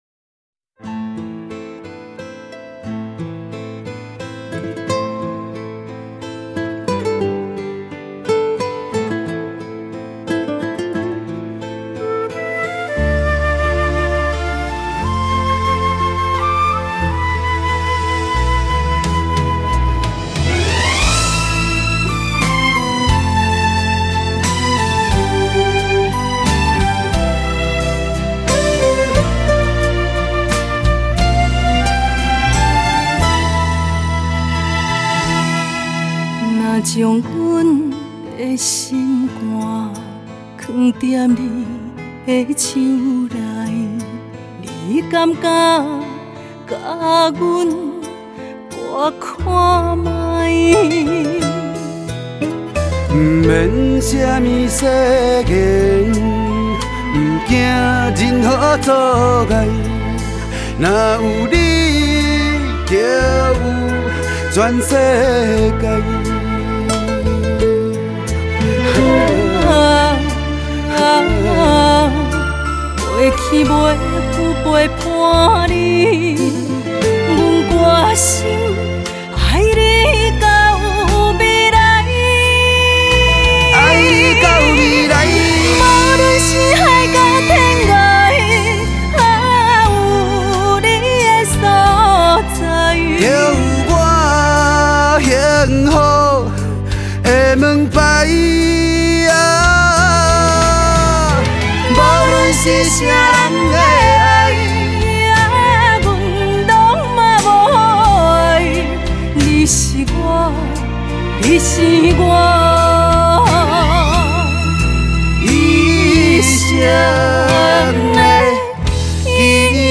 演唱曲风具有日本演歌的特殊唱腔